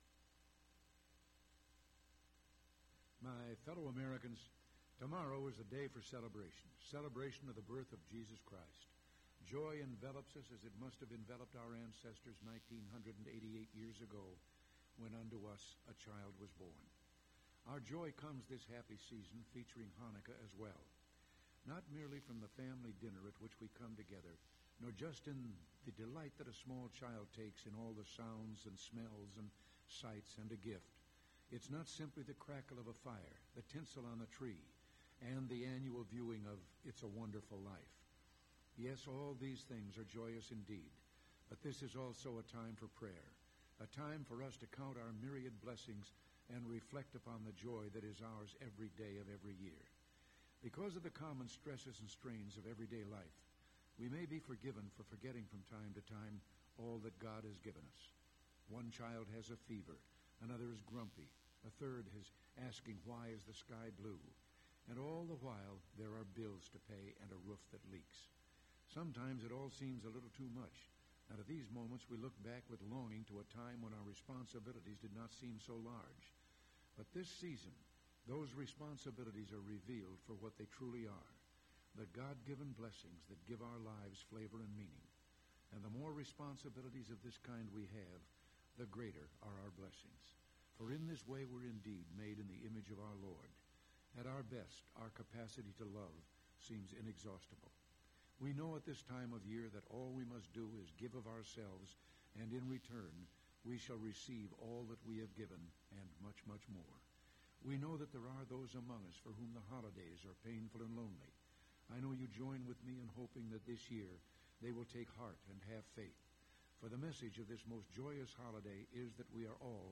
President Reagan’s Radio Broadcast, Edited copy